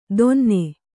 ♪ donne